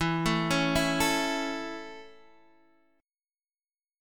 E 6th Suspended 4th